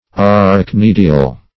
Search Result for " arachnidial" : The Collaborative International Dictionary of English v.0.48: Arachnidial \Ar`ach*nid"i*al\, a. (Zool.)